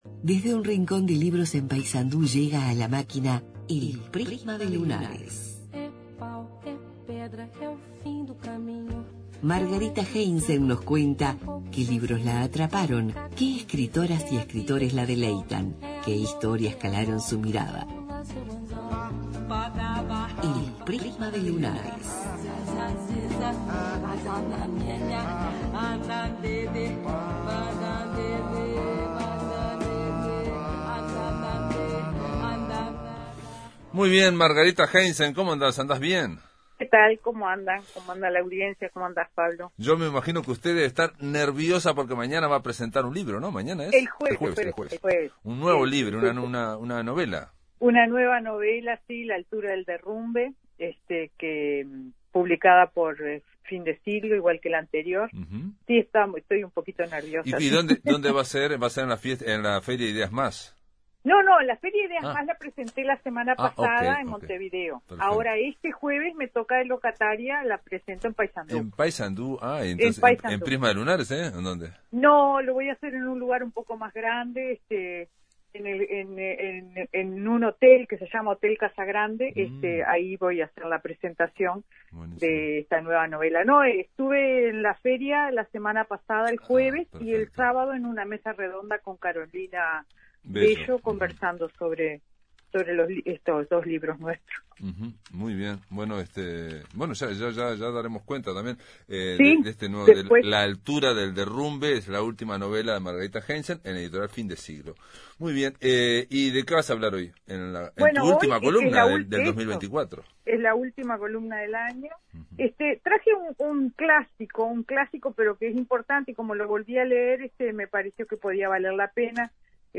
Reseña